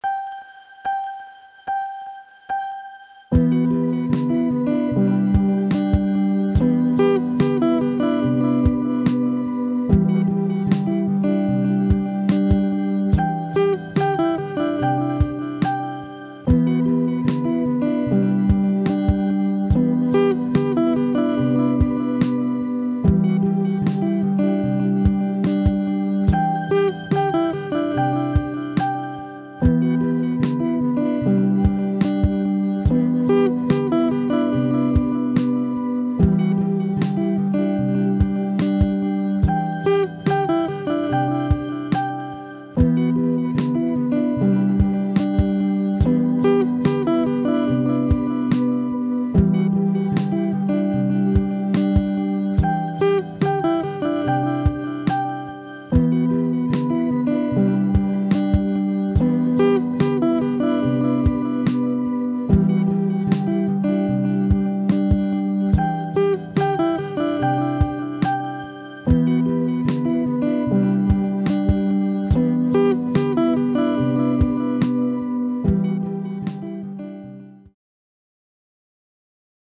Quick loop with the Evoke, guitar, and 404!